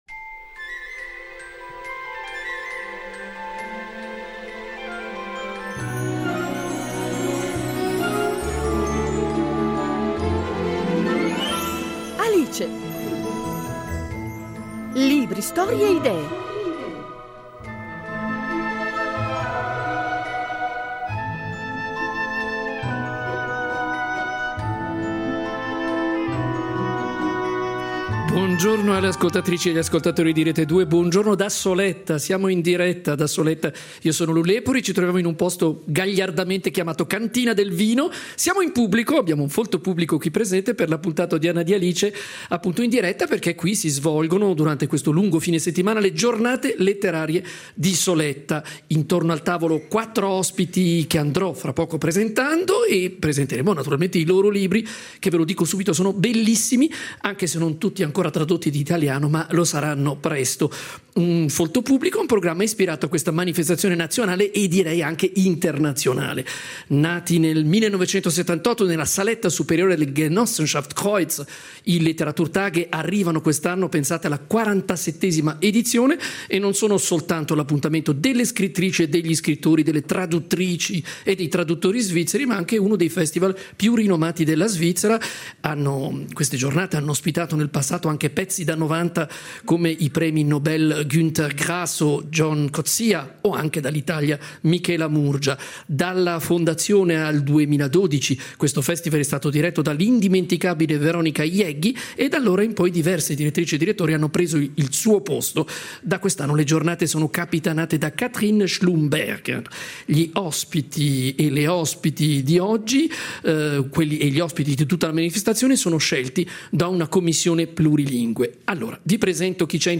In occasione delle 47esime giornate letterarie
“Alice” in diretta da Soletta